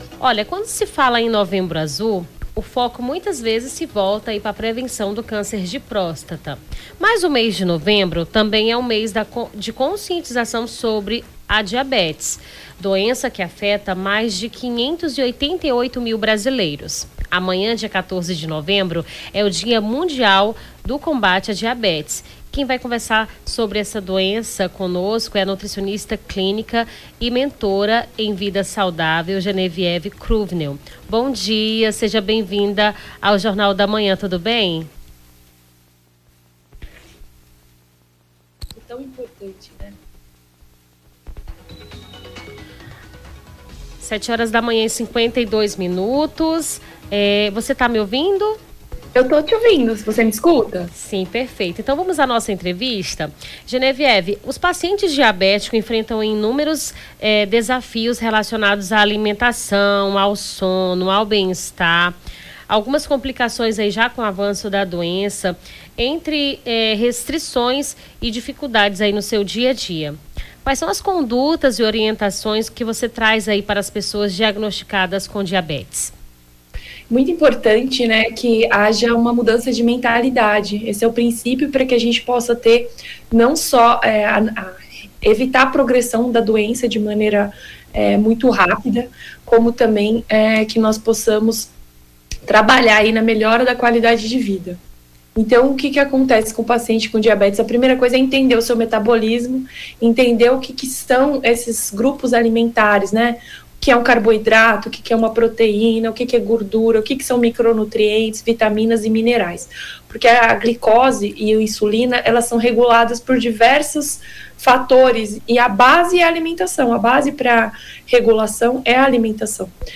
Baixar Esta Trilha Nome do Artista - CENSURA- ENTREVISTA DIA MUNDIAL DA DIABETES (13-11-24).mp3 Foto: internet/ Freepik Facebook Twitter LinkedIn Whatsapp Whatsapp Tópicos Rio Branco Acre diabetes Bem-estar Saúde Animal Nutricionista